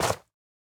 Minecraft Version Minecraft Version 1.21.5 Latest Release | Latest Snapshot 1.21.5 / assets / minecraft / sounds / block / soul_soil / step1.ogg Compare With Compare With Latest Release | Latest Snapshot
step1.ogg